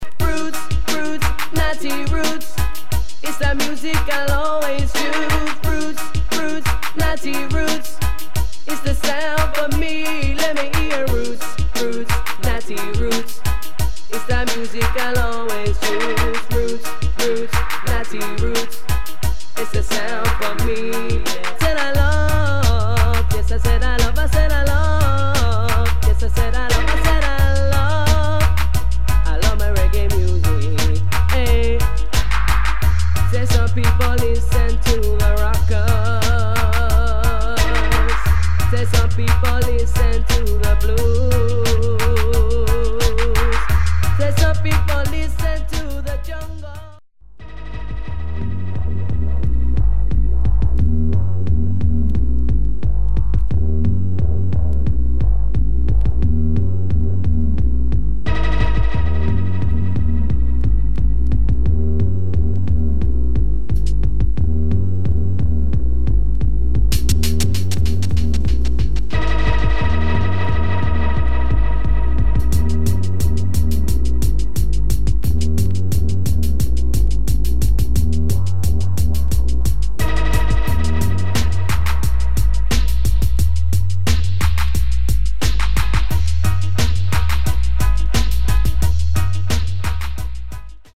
Recorded: Itrol Tower Studios